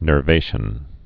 (nûr-vāshən)